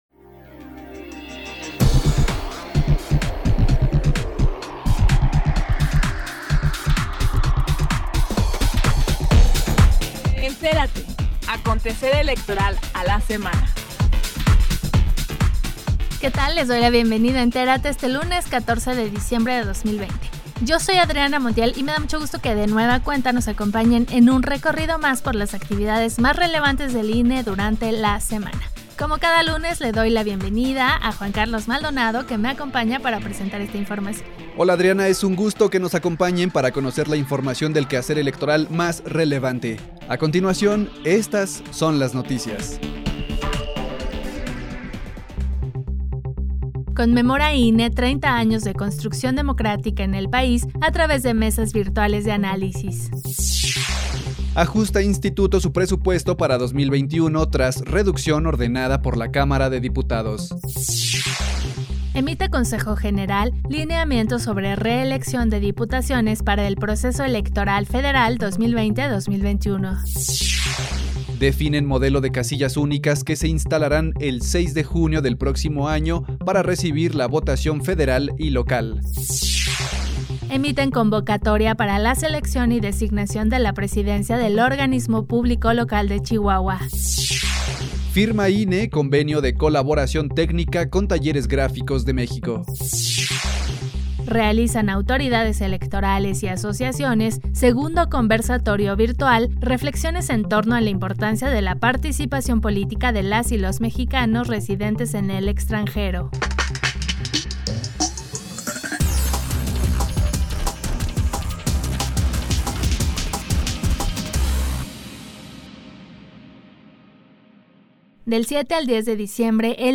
NOTICIARIO 14 DE DICIEMBRE 202